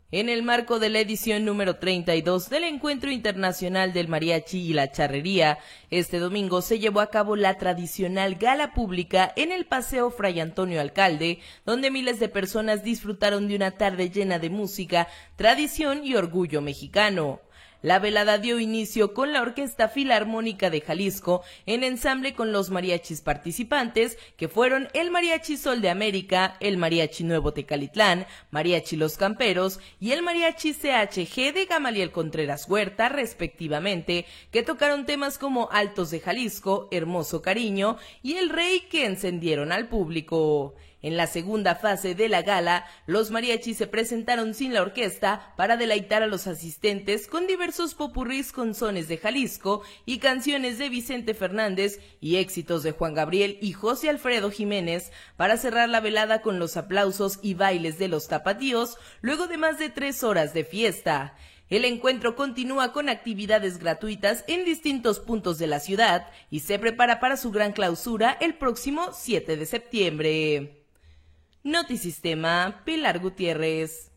NOTA-MARIACHI.m4a